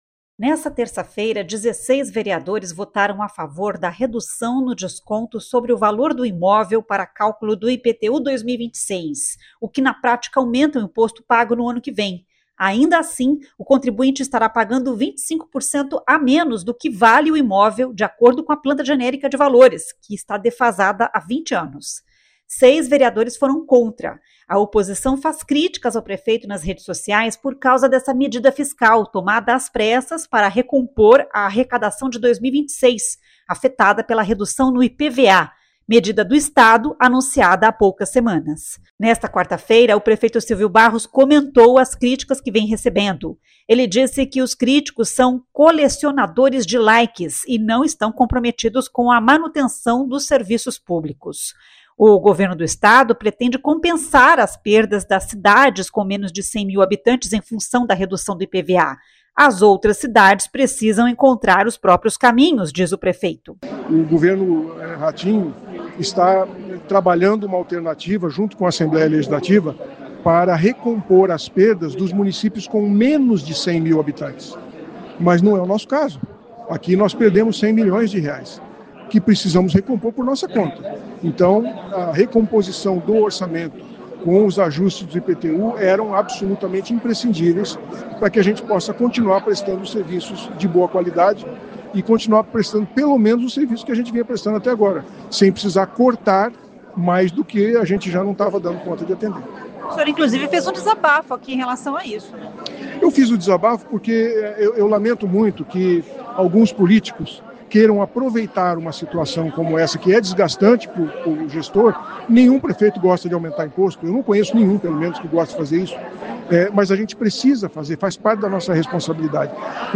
Em evento nesta quarta-feira (1º), o prefeito Sílvio Barros rebateu as críticas que vem recebendo nas redes sociais de vereadores que votaram contra alteração no IPTU para cobrir rombo com queda na arrecadação de IPVA.